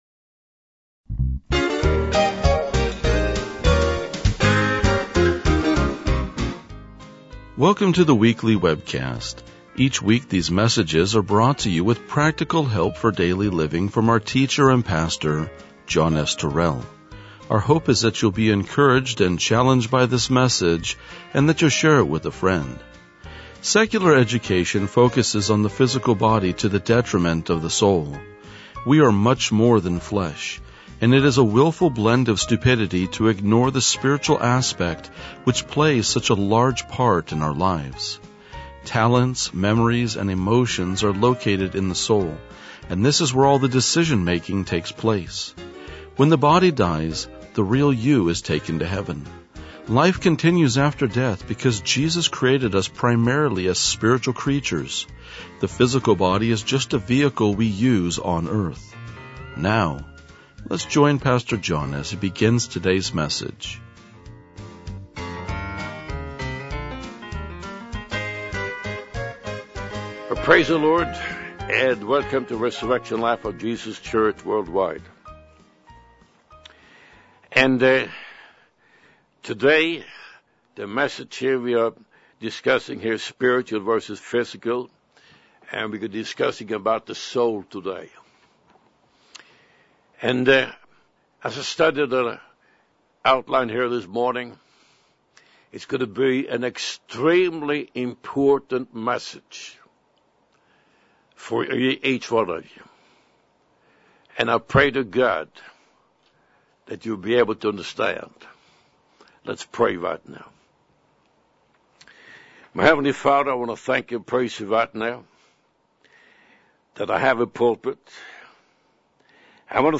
RLJ-2019-Sermon.mp3